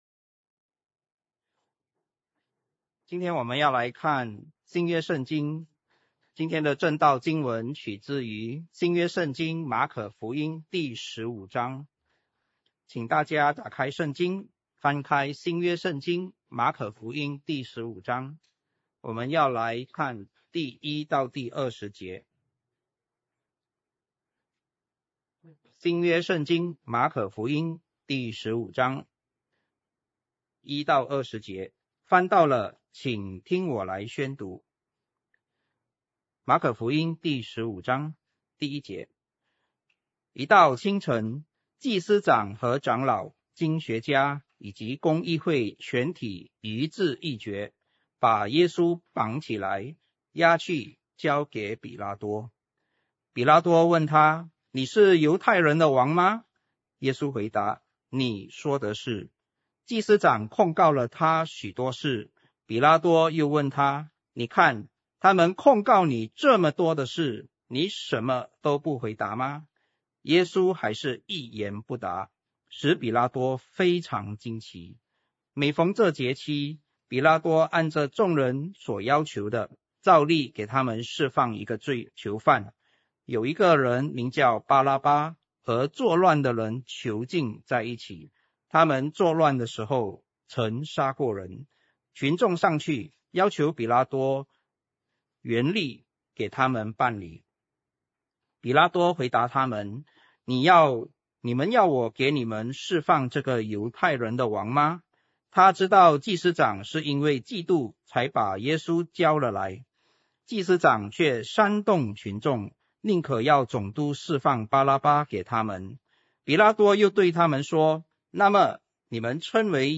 主日证道 2025